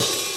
Medicated OHat 4.wav